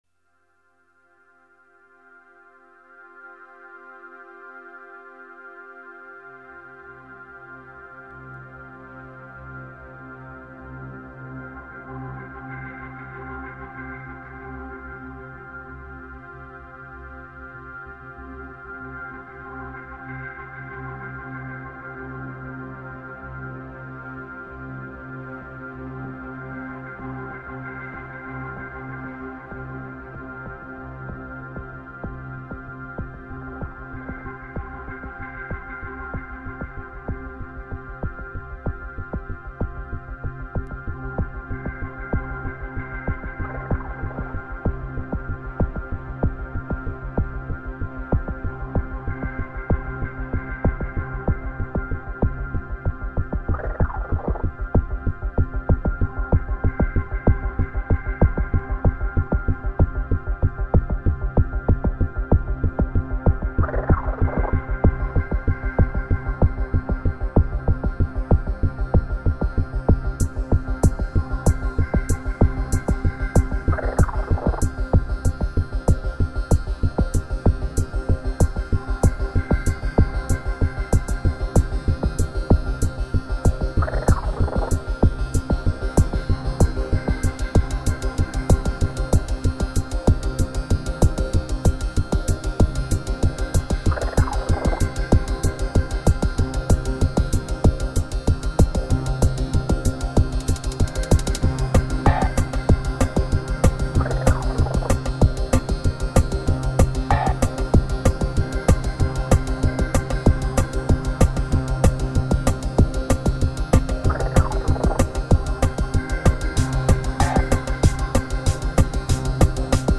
(mpc2000, mc-505, mc-303, mackie 1402 & kaoss-pad)